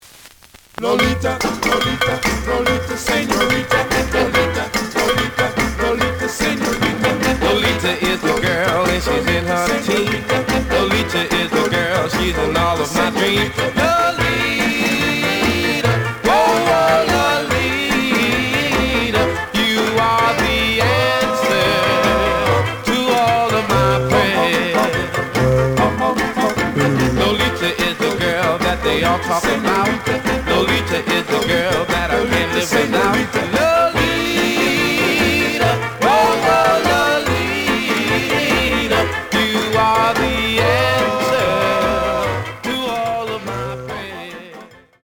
試聴は実際のレコードから録音しています。
●Genre: Rhythm And Blues / Rock 'n' Roll
G+, G → 非常に悪い。ノイズが多い。